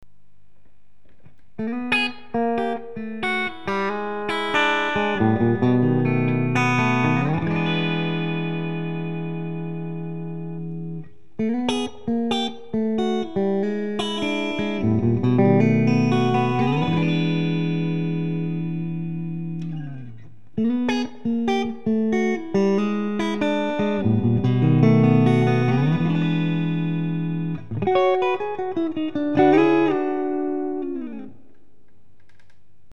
mine de rien j'ai entendu des samples d'un mec qui a acheté une telecaster chez Ishibashi je vous file les liens vers les samples ca arrache tout